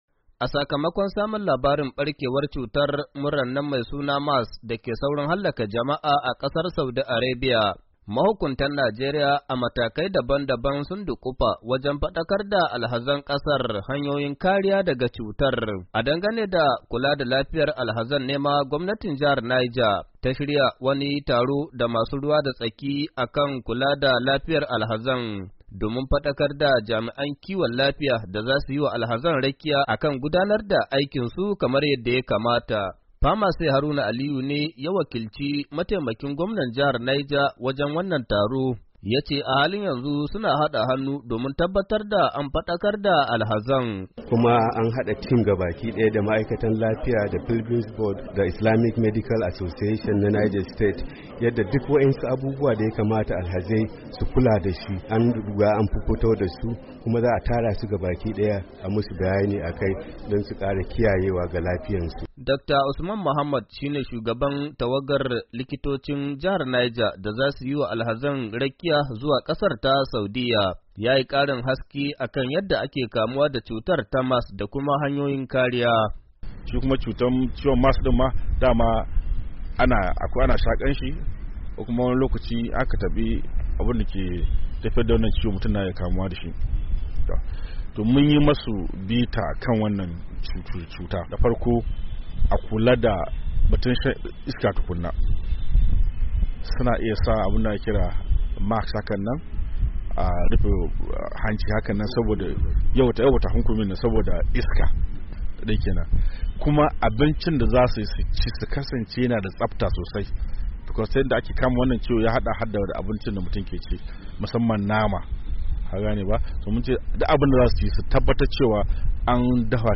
rahoton